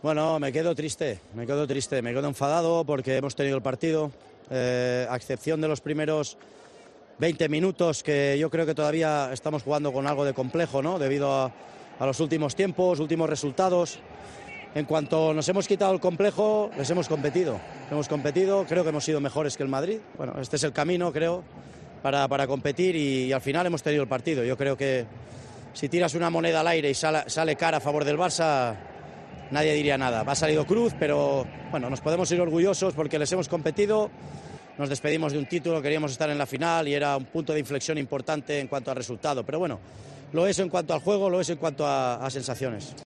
El entrenador del Barcelona ha asegurado en rueda de prensa que, en algunos momentos del partido, "hemos sido mejor que el Real Madrid".